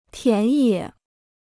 田野 (tián yě): field; open land.